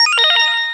player_join.wav